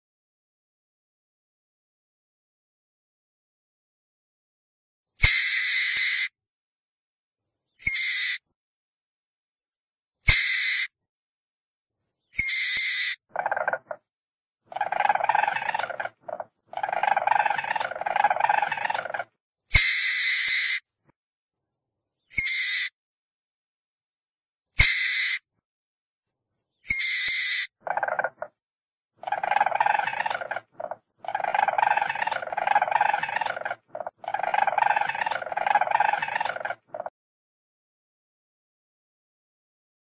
Sonido de la comadreja Sonidos cortos de animales.mp3